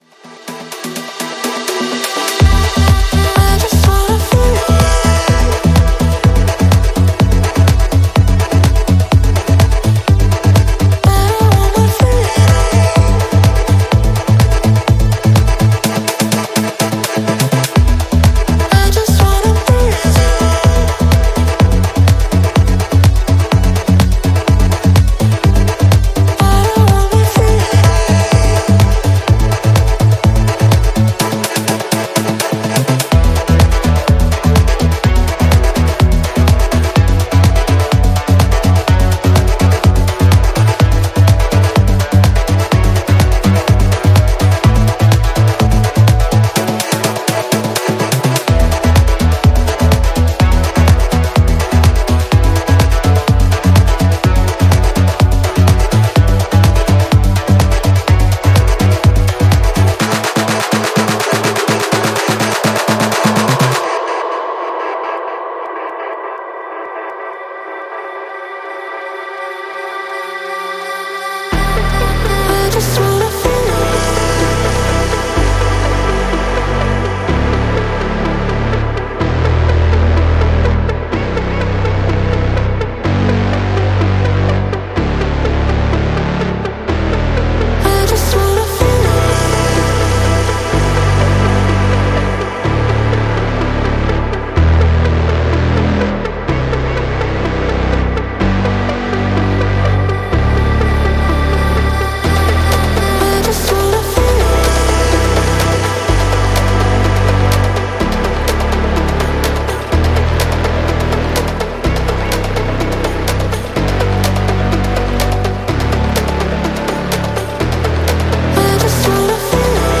Genre: Melodic House